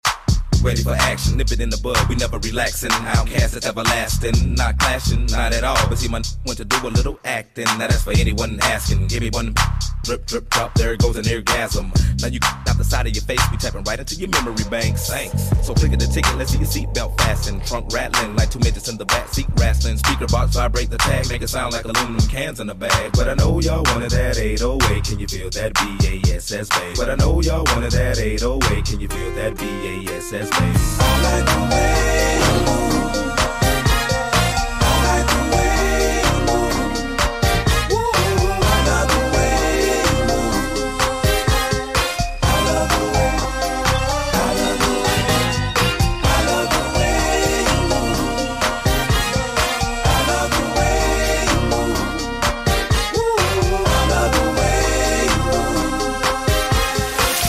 Boom Sound Effects Free Download